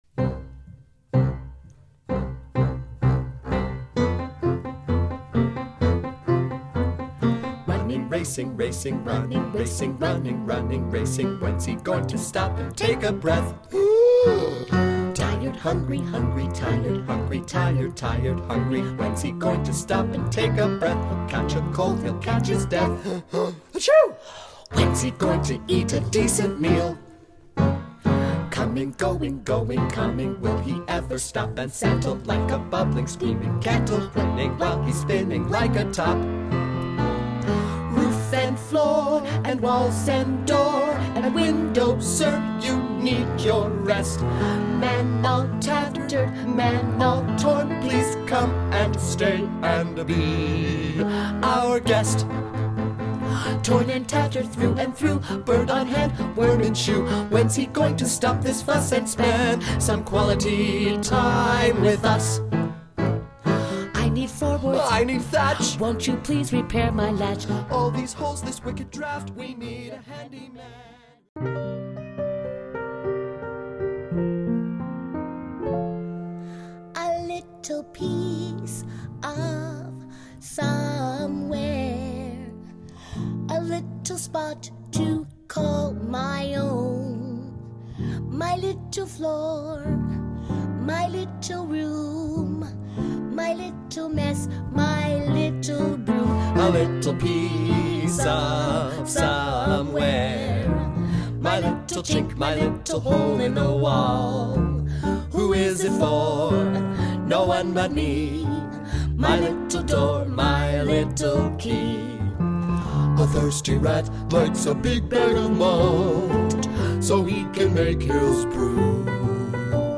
There are two to four actor-singers in each show. A keyboardist delivers a rich accompaniment to the story.